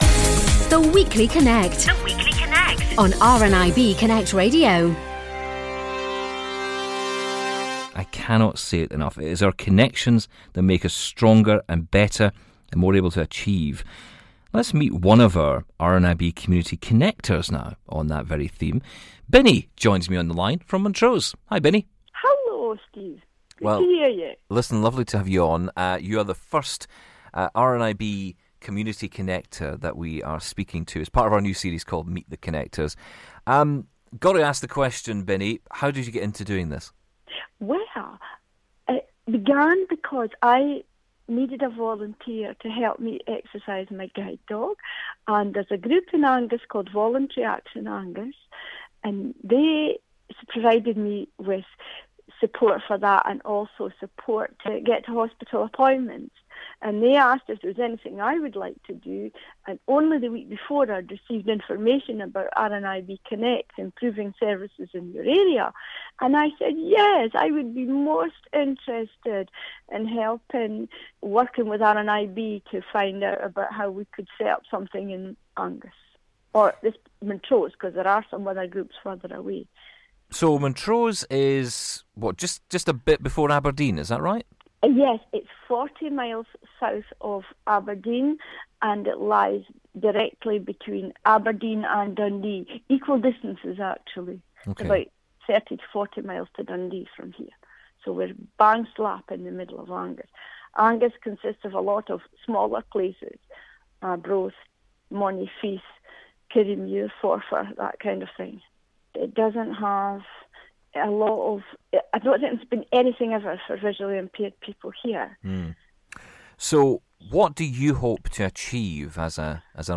Our community superheroes - The Connectors – introduce themselves and the areas they serve.